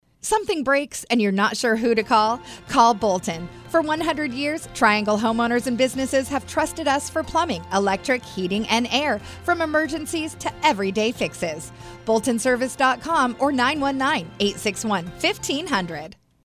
As Heard on Capitol Broadcasting